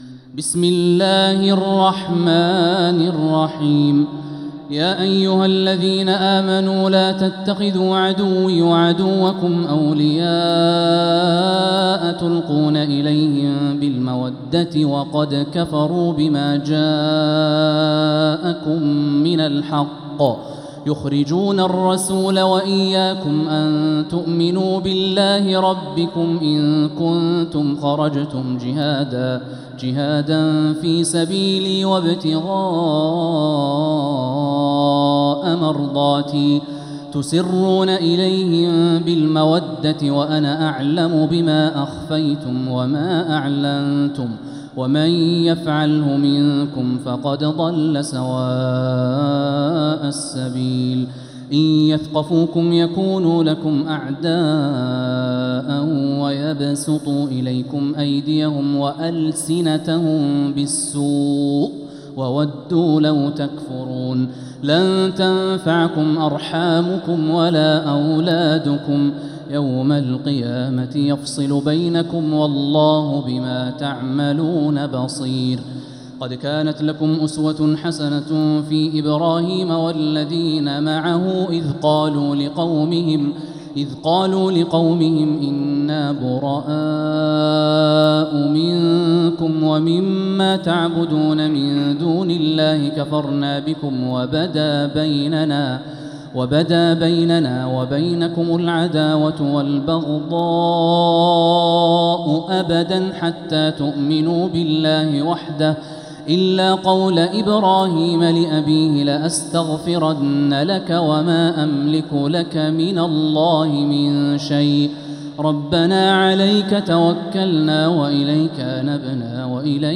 سورة الممتحنة | مصحف تراويح الحرم المكي عام 1446هـ > مصحف تراويح الحرم المكي عام 1446هـ > المصحف - تلاوات الحرمين